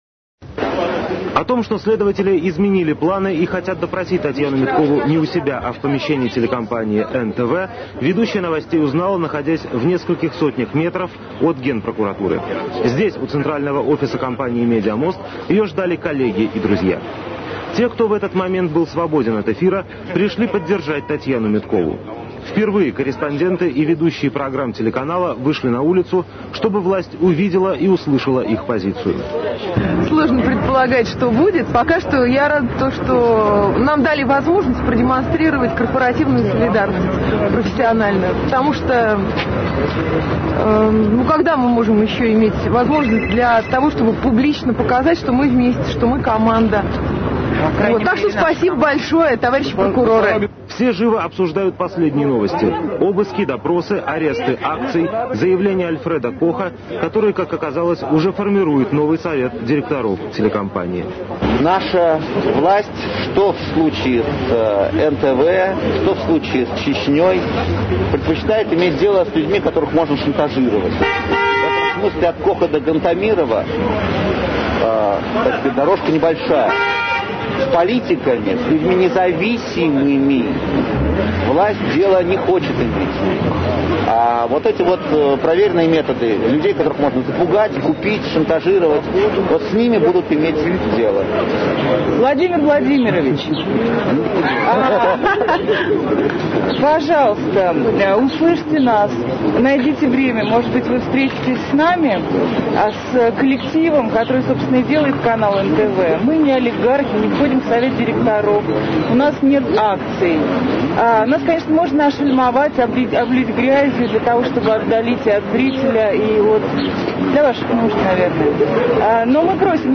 запись трансляции